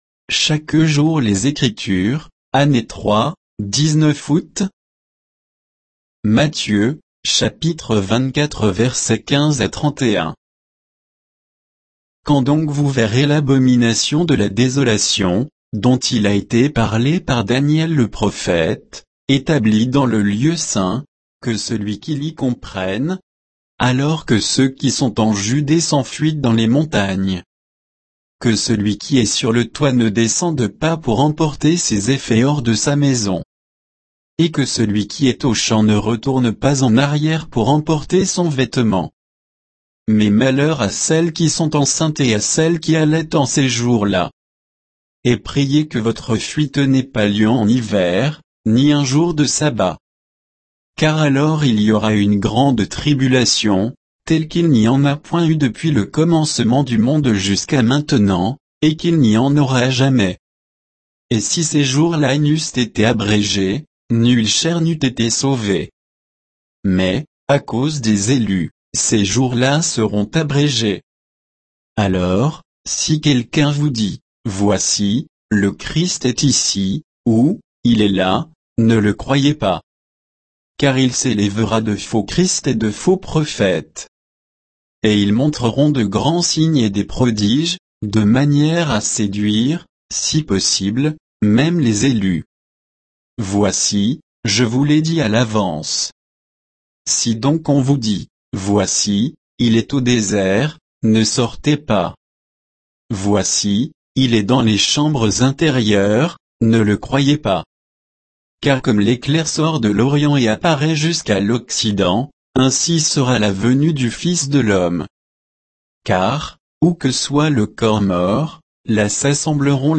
Méditation quoditienne de Chaque jour les Écritures sur Matthieu 24